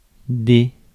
Ääntäminen
Ääntäminen France (Paris): IPA: [de] Tuntematon aksentti: IPA: /dɛ/ IPA: /de.z‿/ IPA: /dɛ.z‿/ Haettu sana löytyi näillä lähdekielillä: ranska Käännös Ääninäyte Pronominit 1. some UK US Luokat Artikkelit Supistumat